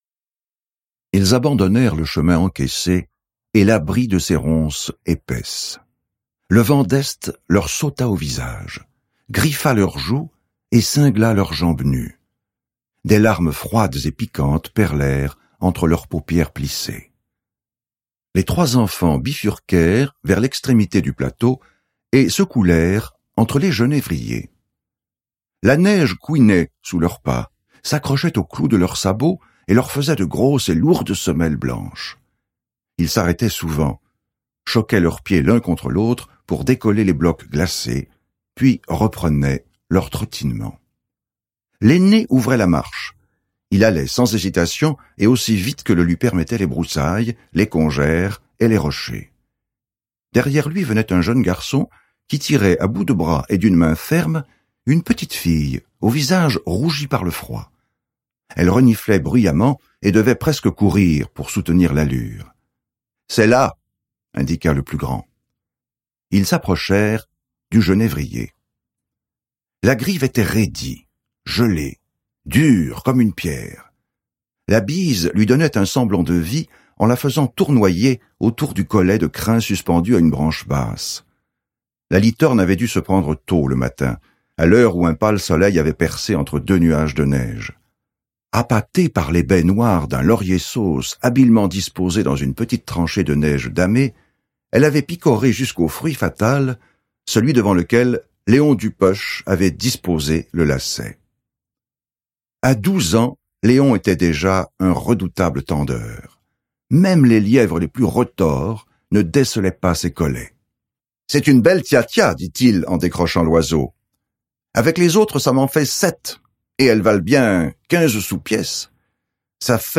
Click for an excerpt - Des grives aux loups de Claude MICHELET